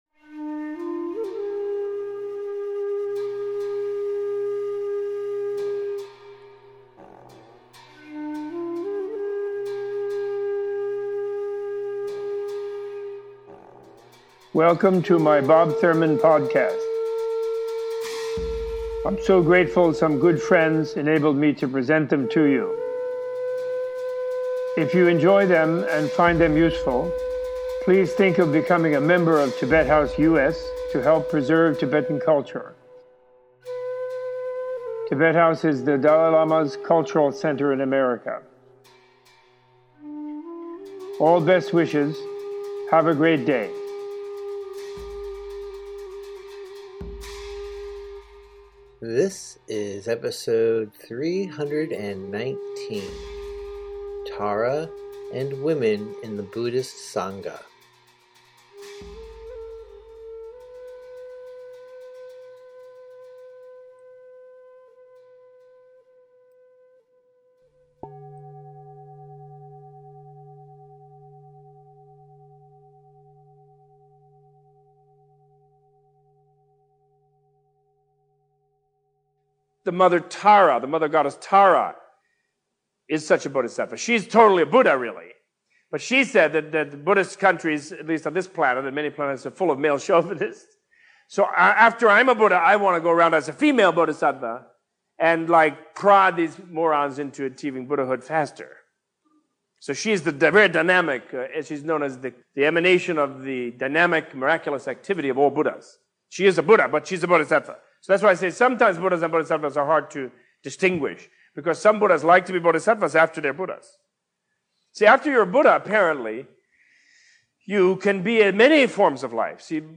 Opening with a discussion of the goddess Tara, Robert Thurman gives a teaching on the differences between Buddhas and Bodhisattvas and the role and place of women in the early Buddhist Sangha, the community of followers of the Buddha and his teachings. This podcast includes an extended discussion of the symbolism of the Wheel of Dharma and the value of developing selflessness on the spiritual path.